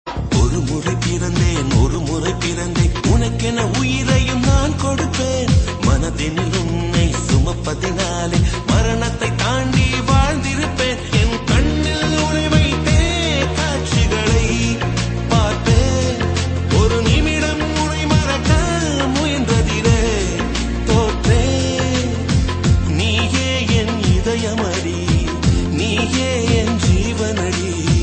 best flute ringtone download
romantic ringtone download
tamil ringtone